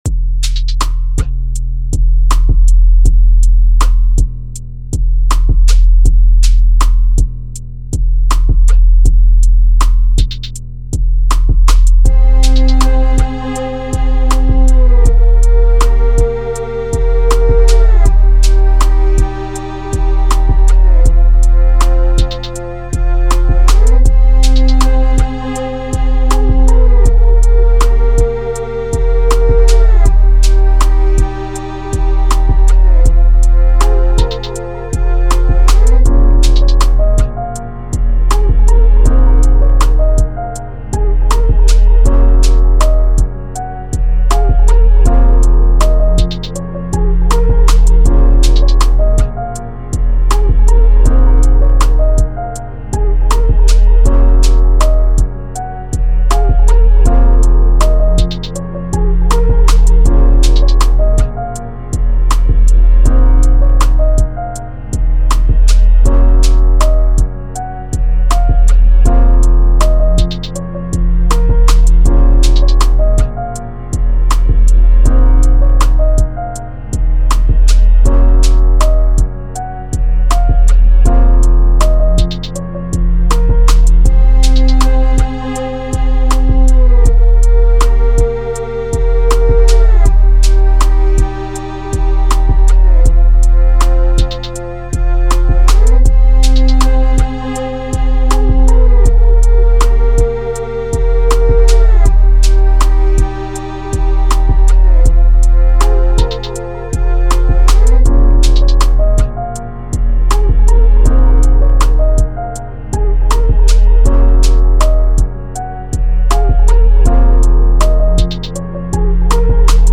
R&B type beat , rap type beats